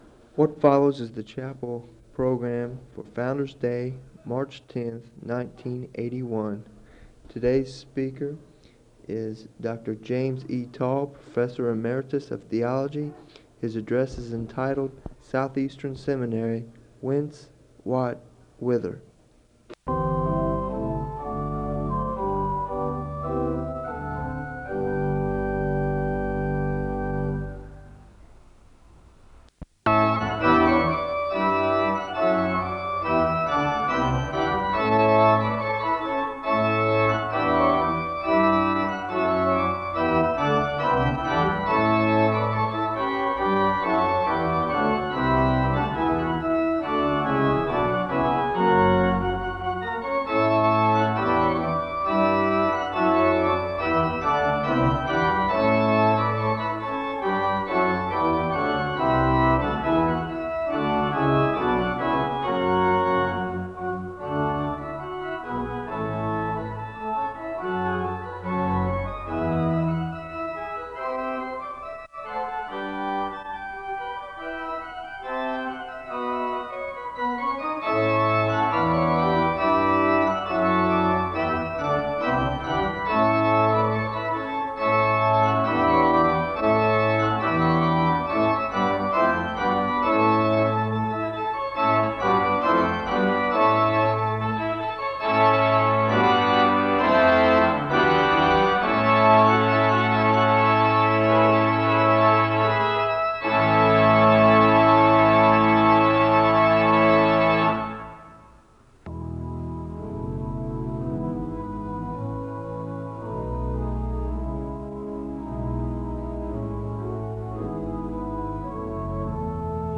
The service begins with organ music (0:00:00-0:07:28).
SEBTS Chapel and Special Event Recordings